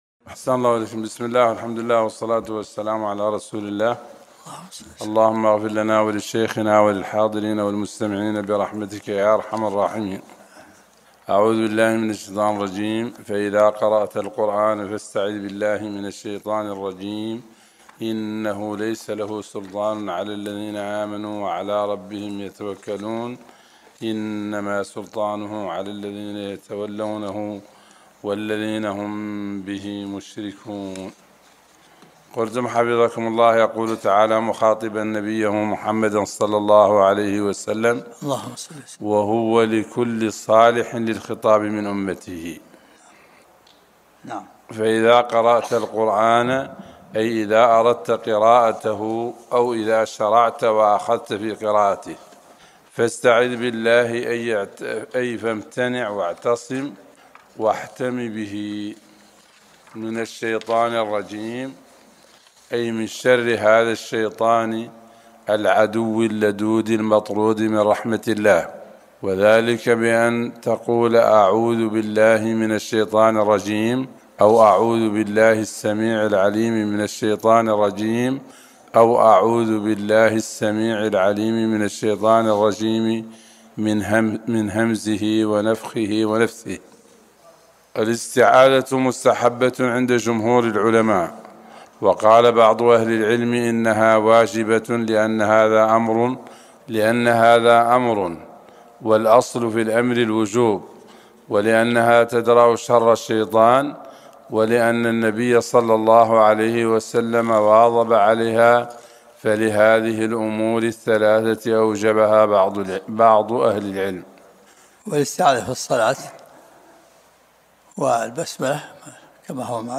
الدرس الثاني عشر من سورة النحل